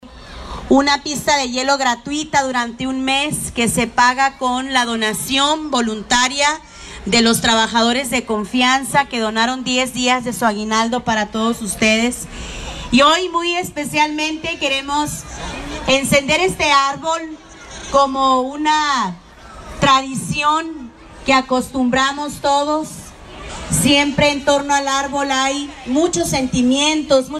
La presidenta municipal Célida López expresó que es un espacio de tradición y reunión para los ciudadanos.
81219-CÉLIDA-LÓPEZ-ARBOL-NAVIDEÑO.mp3